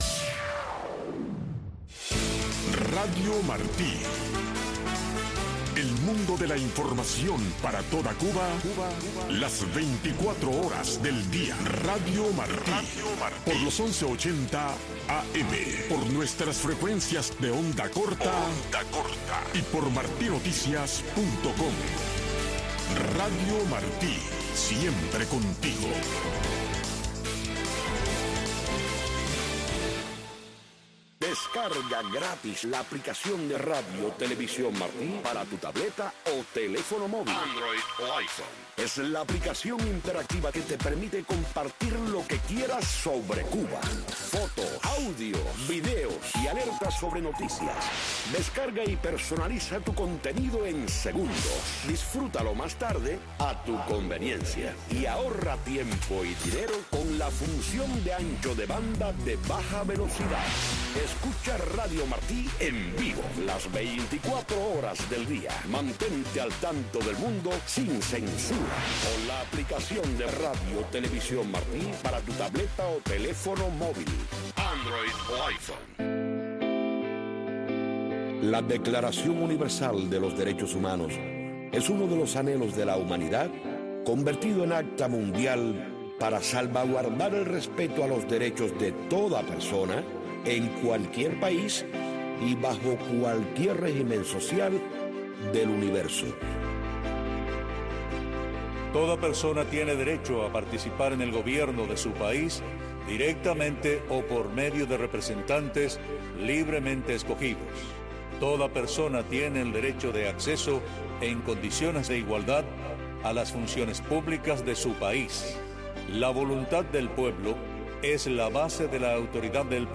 El programa radial Alternativa es un programa de panel de expertos